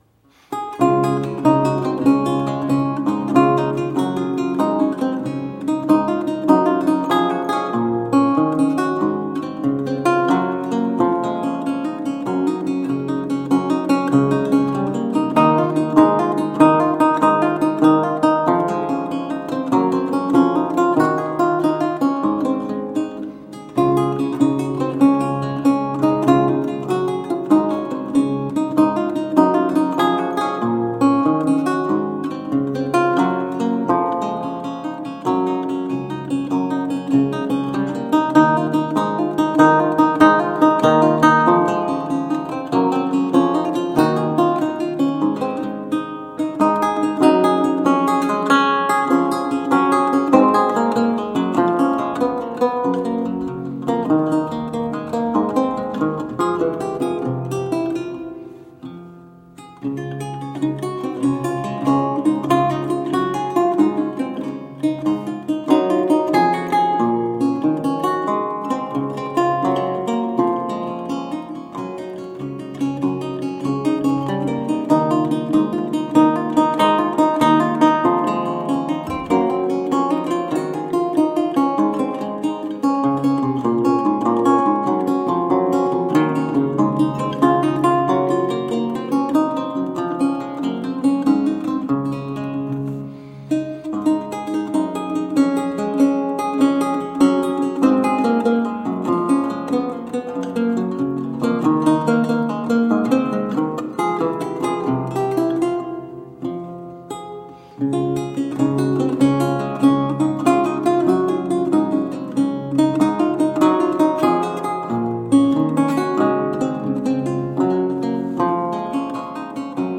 Vihuela, renaissance and baroque lute
Classical, Baroque, Renaissance, Instrumental
Lute